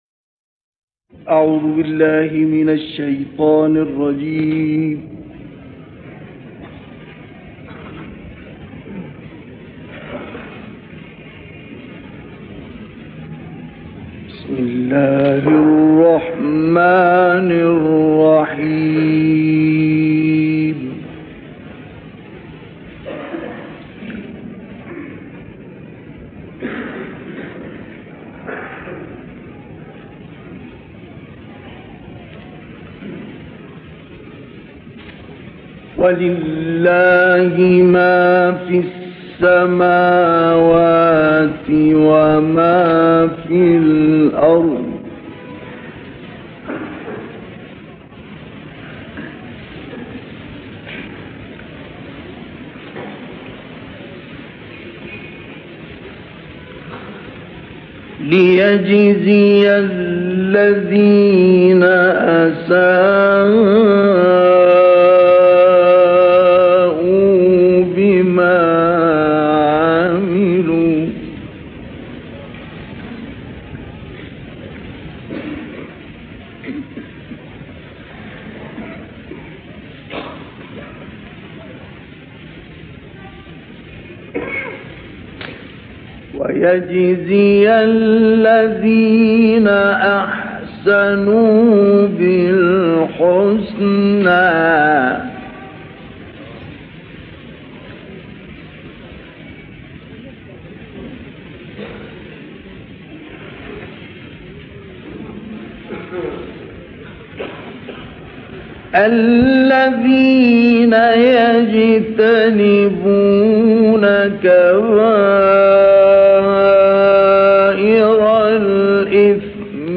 تلاوتی در دهه پنجاه میلادی از «مصطفی اسماعیل»
گروه شبکه اجتماعی: تلاوت تازه منتشر شده از سوره‌های مبارکه نجم، قمر و الرحمن توسط مصطفی اسماعیل که در مسجد الاقصی اجرا شده است، ارائه می‌شود.
این تلاوت در دهه پنجاه میلادی در مسجد الاقصی اجرا شده و مدت زمان آن 38 دقیقه است.